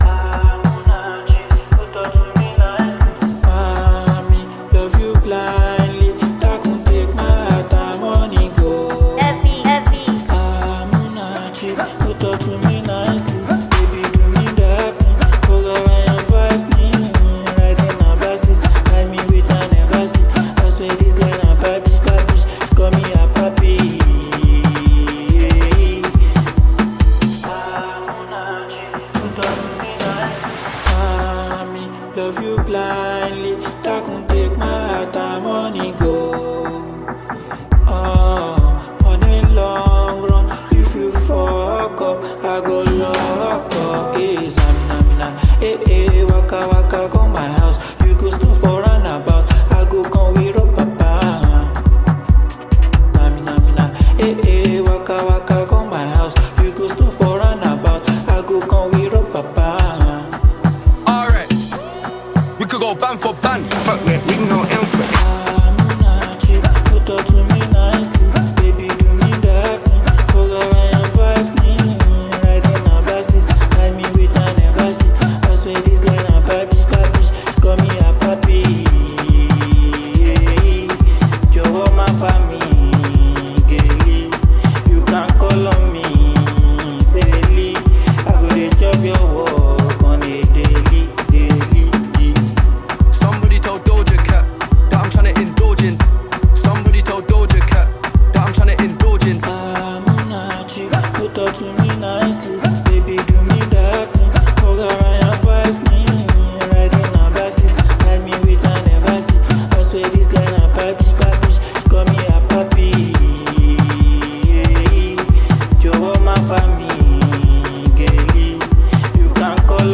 The Nigerian music sensation
With its infectious beat and captivating vocals